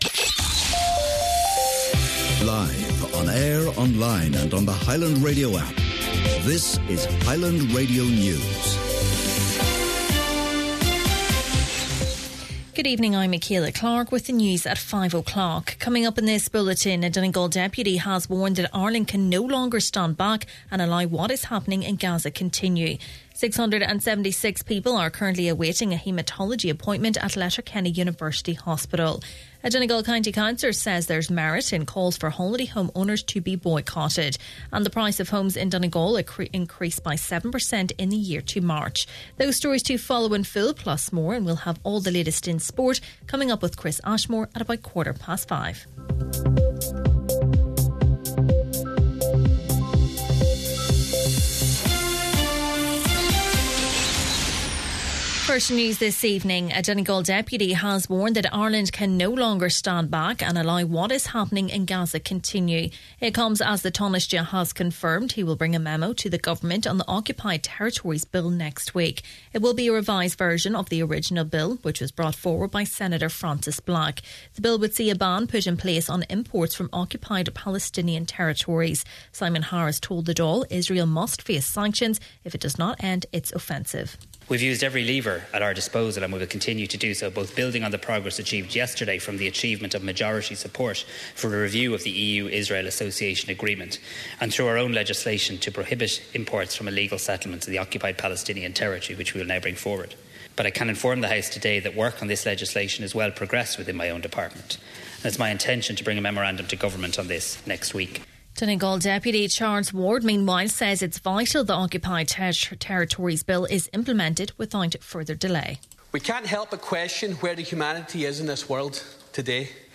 Main Evening News, Sport and Obituaries – Wednesday, May 21st